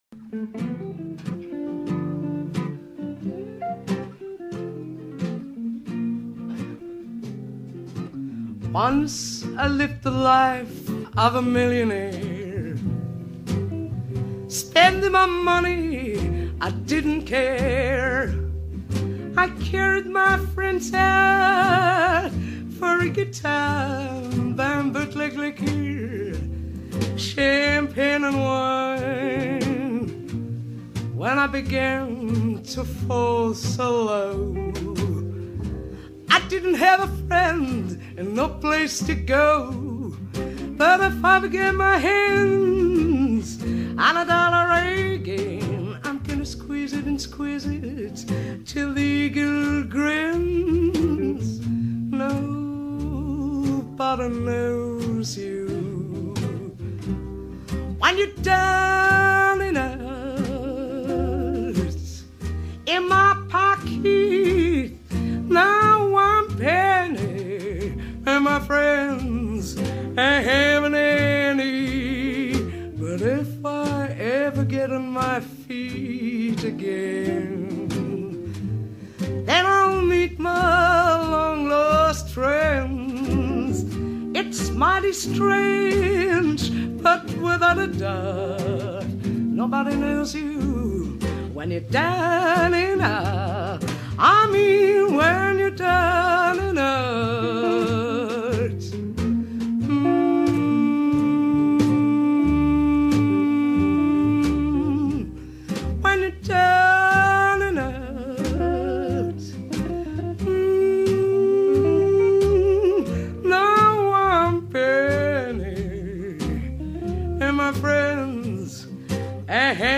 仏蘭西で、1958年ごろからギター１本のブルーズ歌手